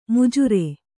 ♪ mujare